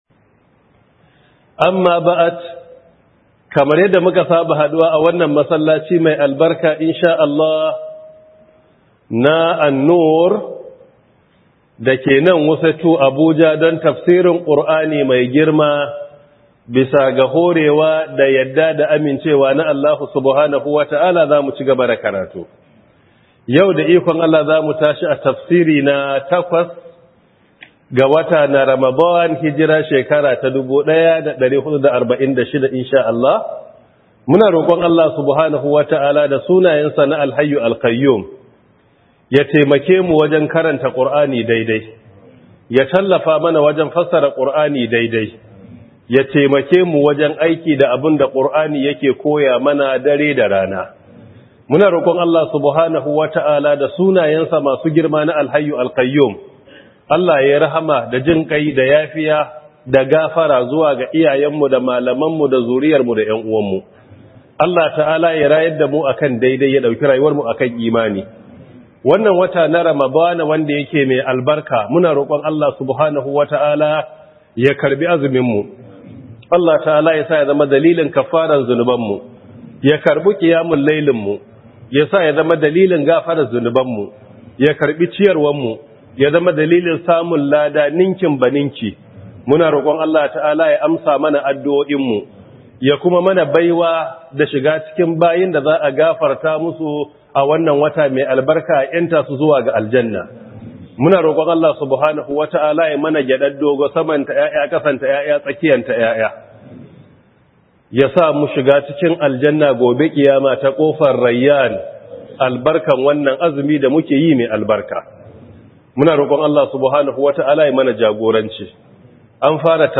Ramadan Tafseer by Prof Isah Ali Ibrahim Pantami 1446AH/2025